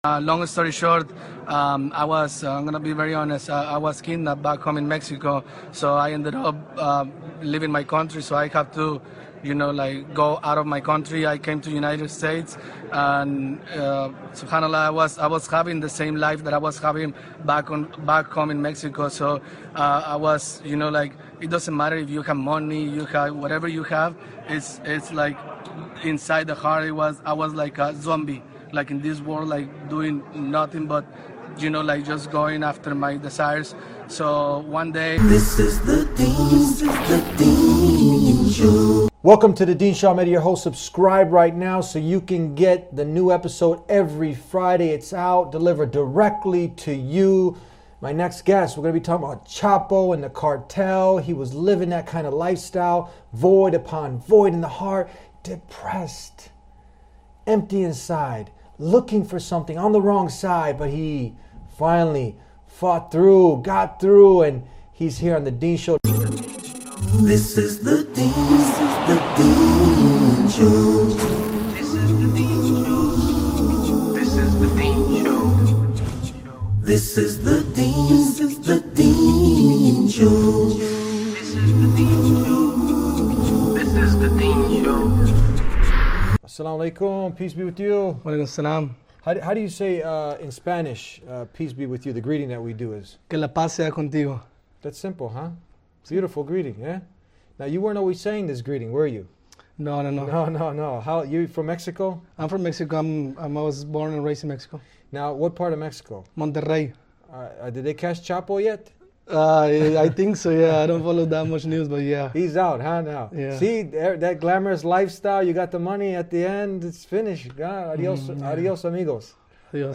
In a captivating episode of The Deen Show, the guest shares his compelling journey from a life entwined with the **”El Chapo” Cartel** to finding peace and purpose in Islam. Kidnapped from Mexico and faced with a perilous lifestyle, he recounts the harrowing experiences that led him to question his existence.